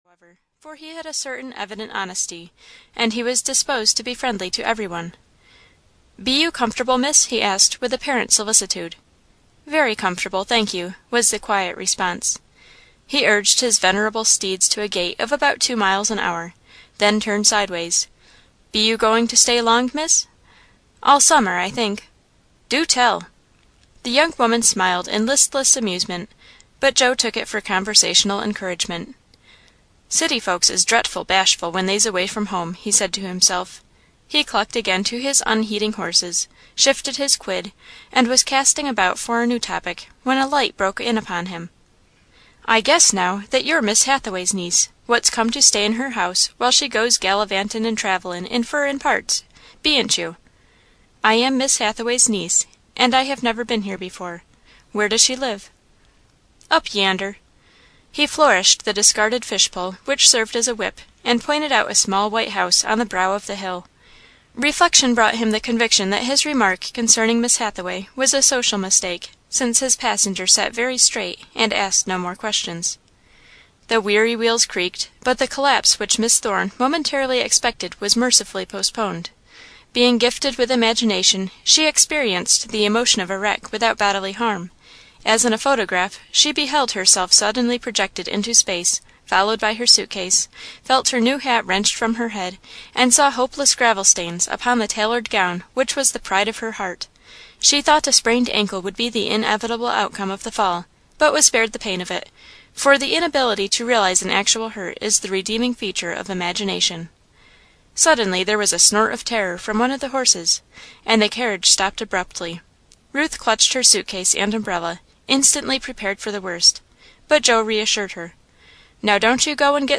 Lavender and Old Lace (EN) audiokniha
Ukázka z knihy